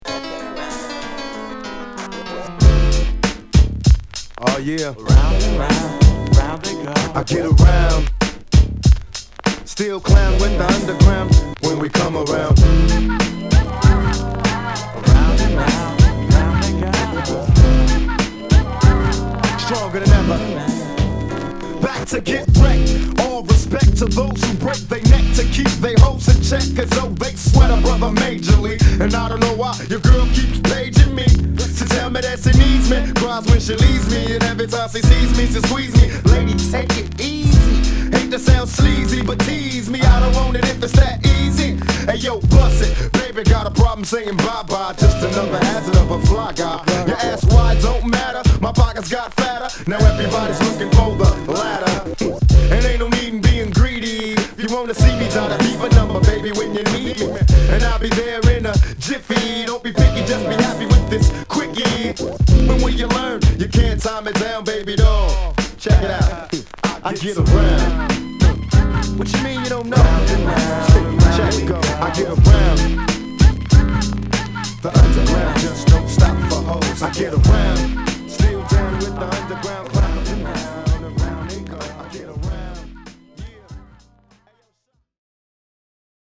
90's Hip Hop Classic!!